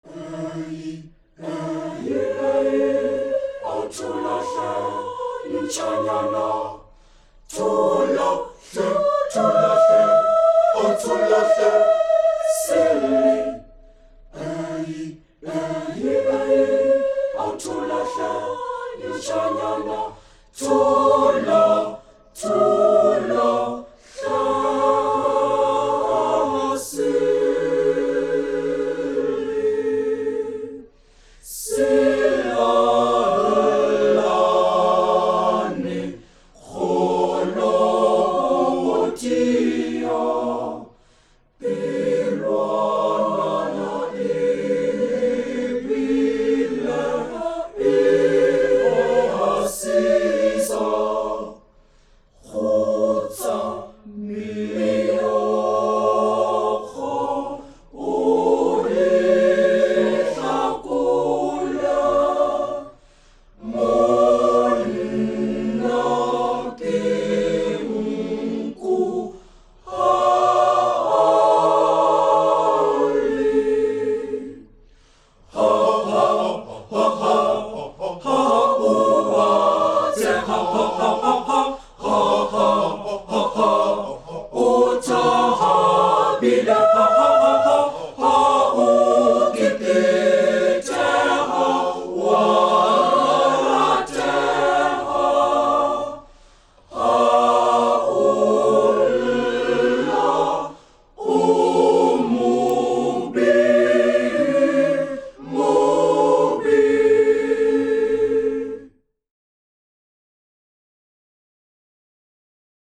Type: Studio Recording Performers
Originally recorded in 1980